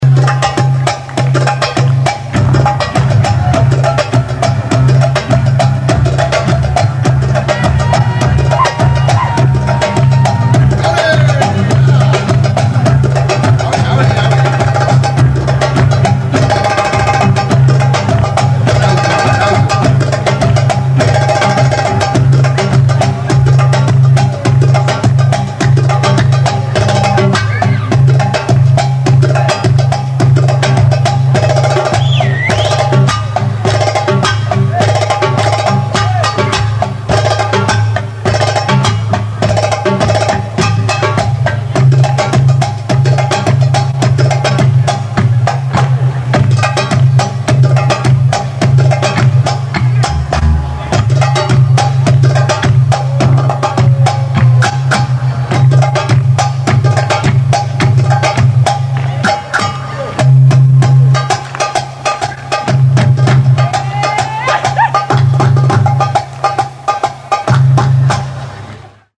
is the tabla virtuoso featured on